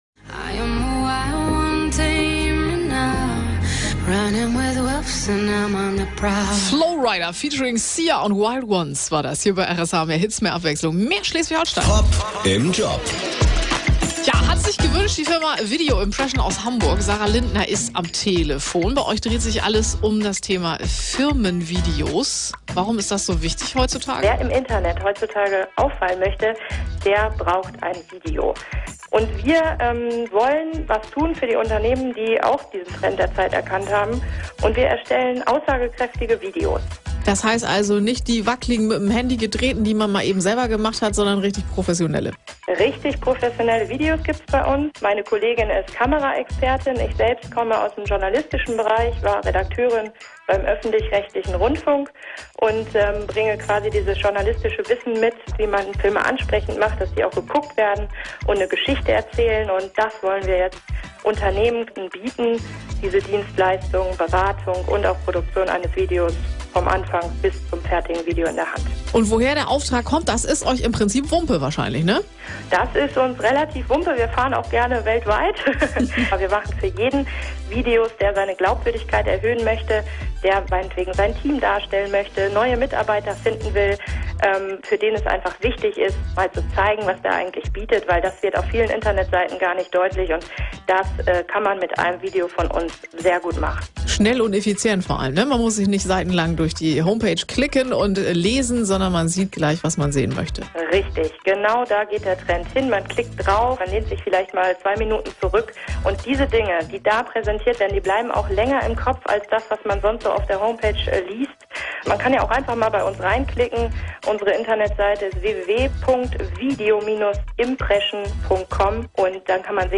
Wir von Video Impression wurden interviewt von einem lokalen Radiosender. Dabei ging es auch um die Bedeutung von Imagevideos für lokale Unternehmen und deren Webseiten.
Radiointerview
Interview-RSH.mp3